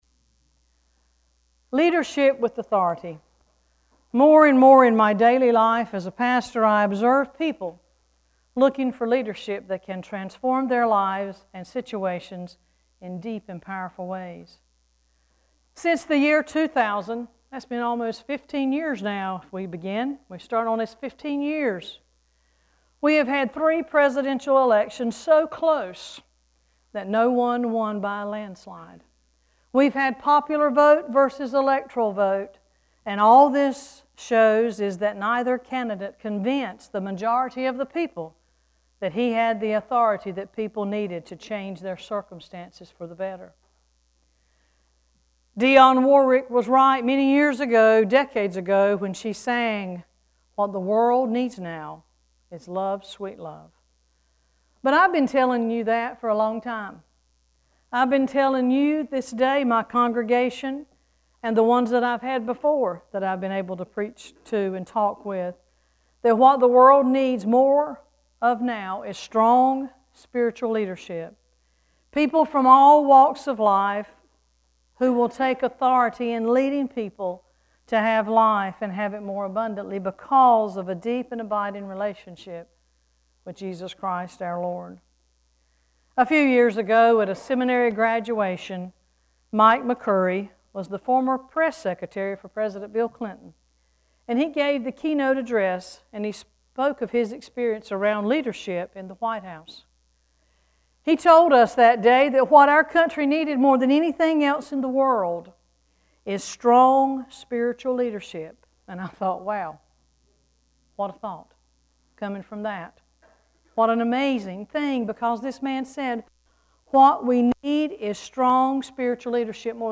2-1-sermon.mp3